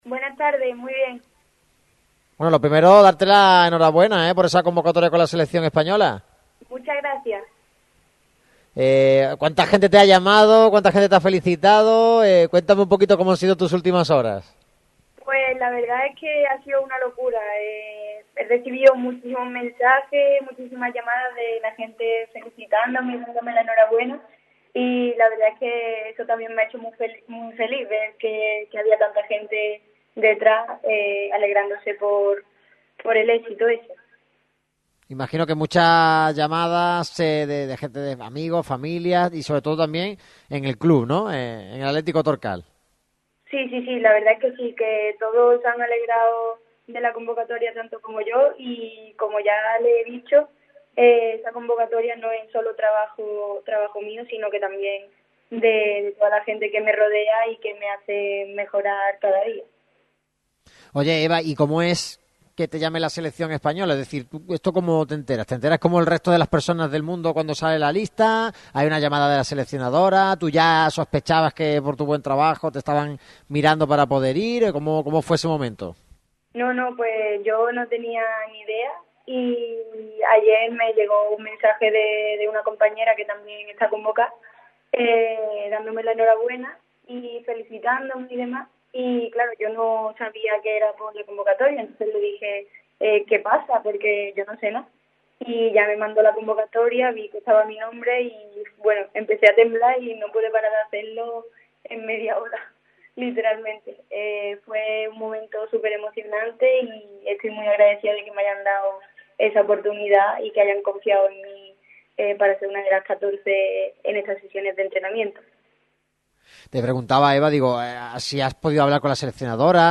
Primera salida de la semana y en uno de nuestros lugares preferidos favoritos: Ford Garum Motor. Un Merchán en Directo cargado de mucha actualidad, información y entrevistas y debate.